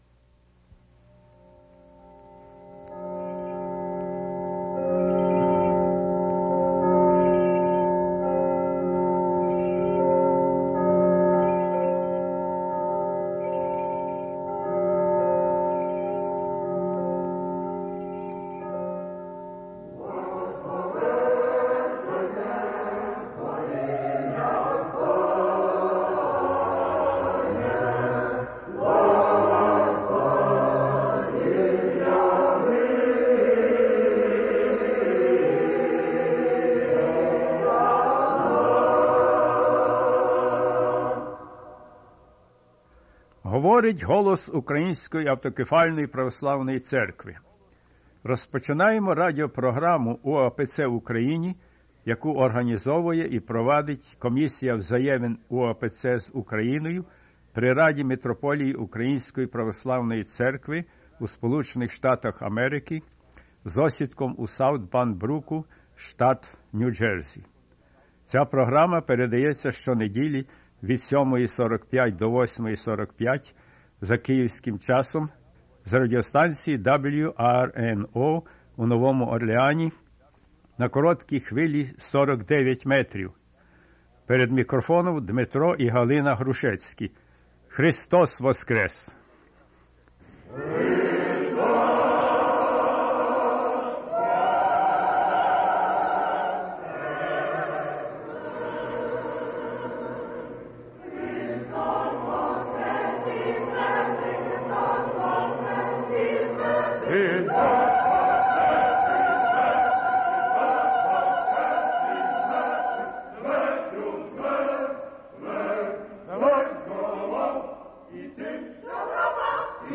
Gospel reading and "Spiritual Discussion"
Radio excursion through the UOC of USA Metropolia Center